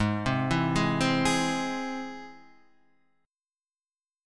Ab+ chord